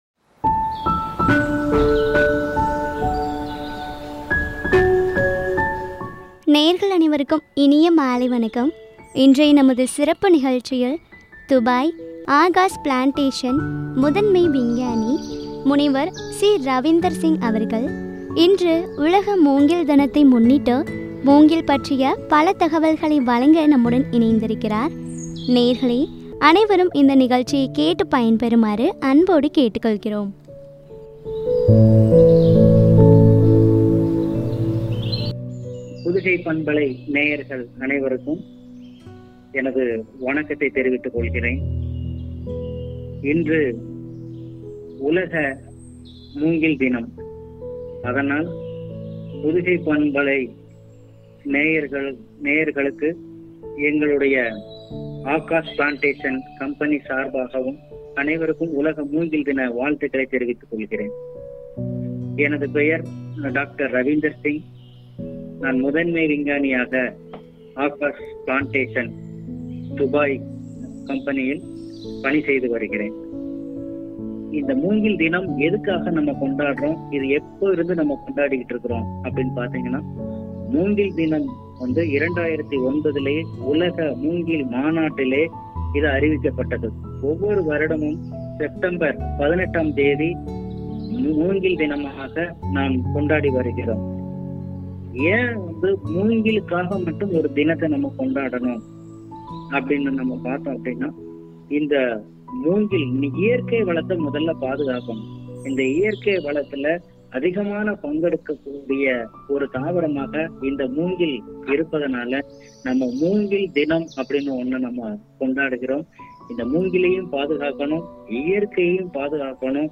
பயன்களும் என்ற தலைப்பில் உரையாடல்.